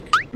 wink Meme Sound Effect
wink.mp3